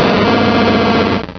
pokeemerald / sound / direct_sound_samples / cries / ursaring.aif